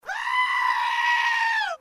Sound Effects
Goat Screaming